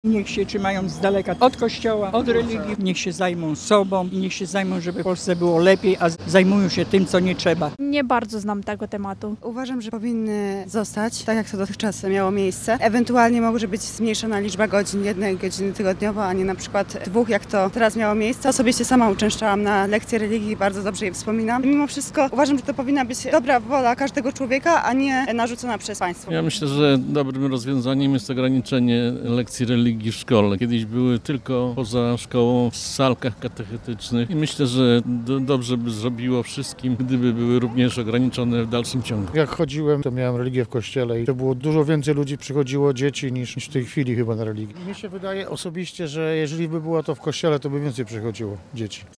01_sonda.mp3